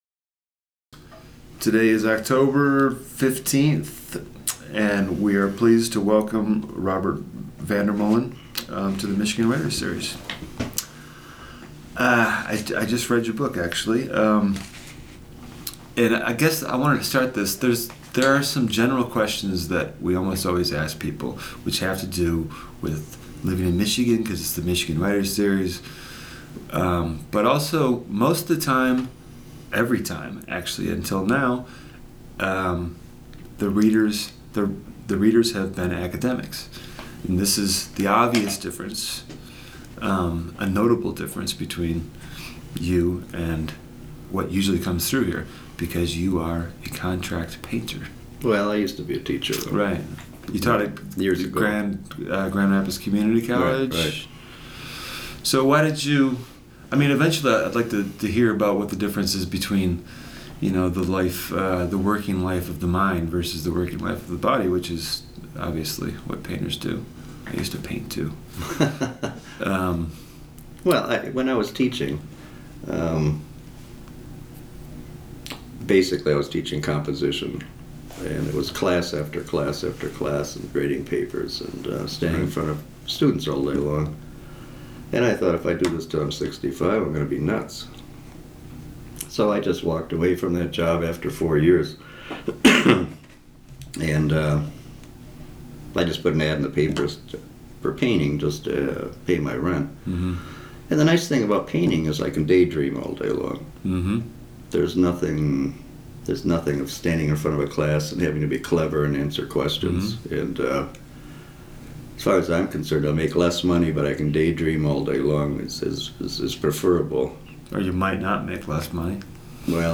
Part of the MSU Libraries' Michigan Writers Series. Held at the MSU Main Library.
Recorded at the Michigan State University Libraries by the Vincent Voice Library on Oct. 15, 2004.